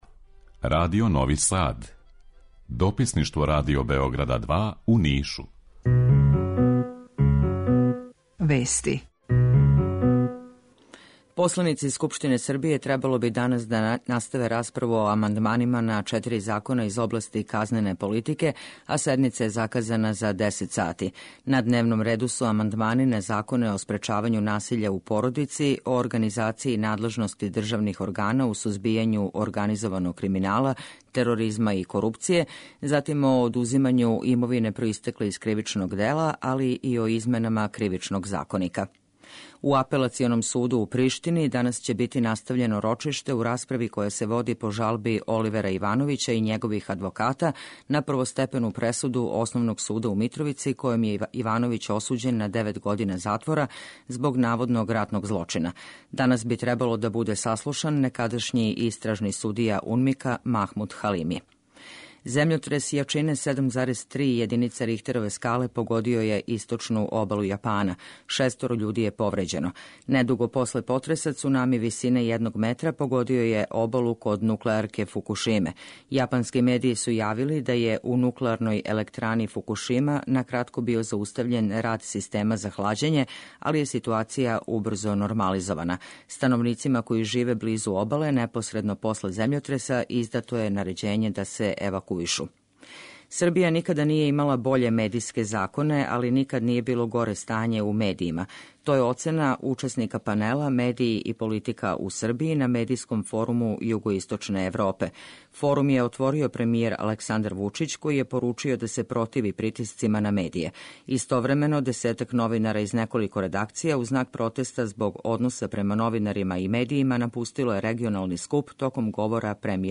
У два сата, ту је и добра музика, другачија у односу на остале радио-станице.